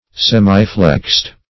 Semiflexed \Sem"i*flexed`\, a.